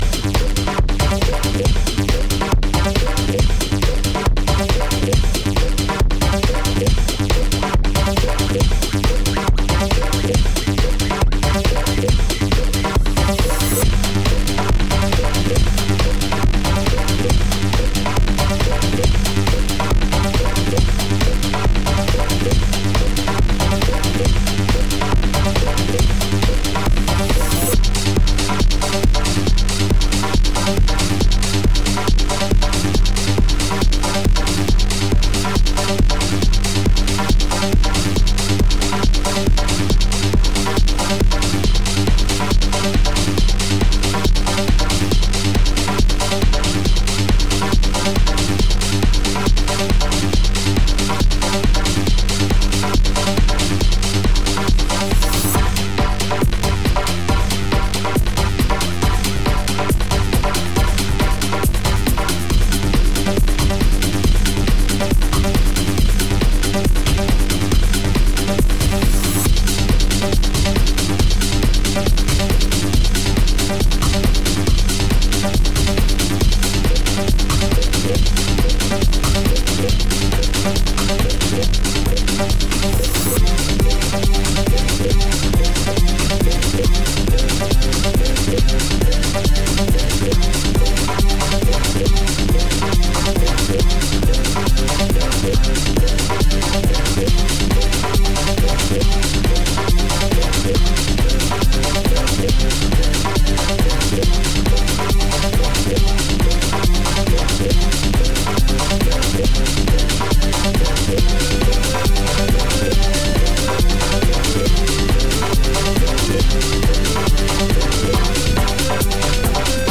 Lose yourself in the electronic.